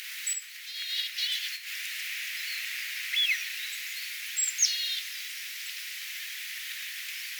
hömötiaisen huomioääntä
tuollaista_huomioaanta_mahdollisesti_sellaisella_siella_lokkien_pesupaikalla_pesivalla_homotiaisella.mp3